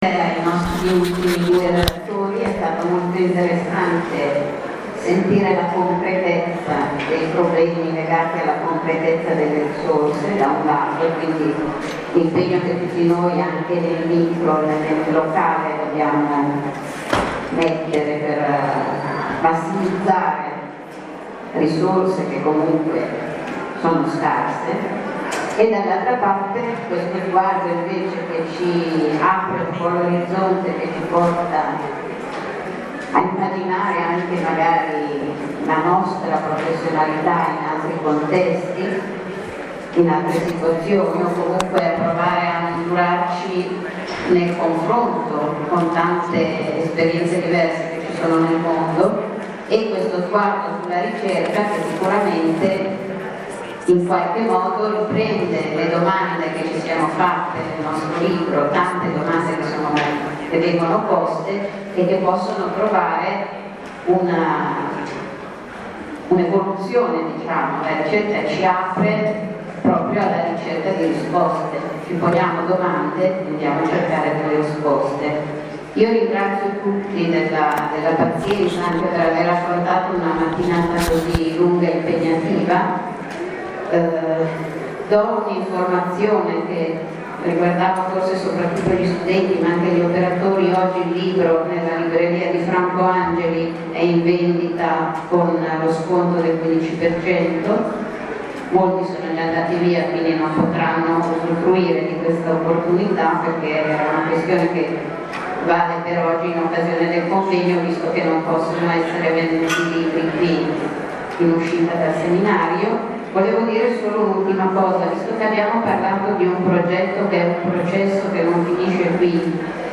Seminario: Sguardi sul servizio sociale, esperienze e luoghi di una professione che cambia, Università di Milano Bicocca, 4 novembre 2016